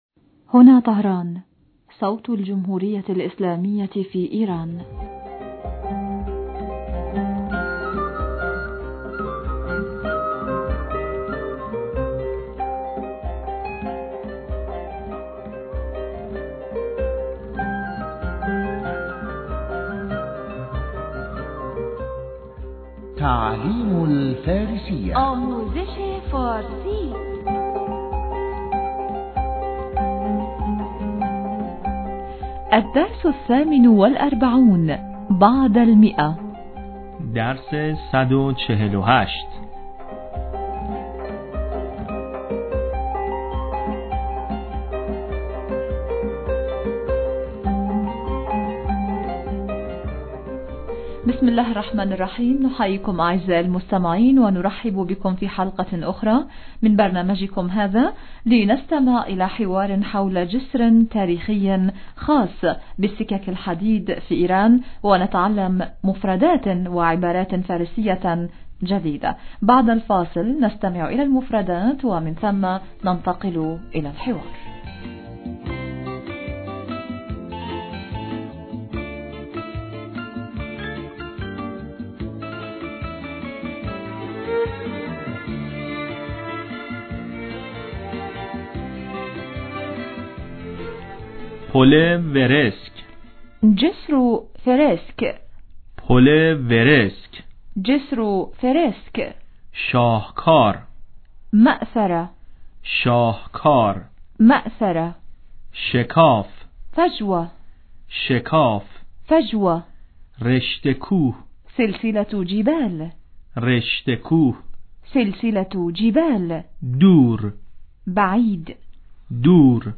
نحييكم ونرحب بكم في حلقة أخري من برنامجكم هذا، لنستمع إلي حوار حول جسر تاريخي خاص بالسكك الحديدية في ايران ونتعلّم مفردات وعبارات فارسية جديدة.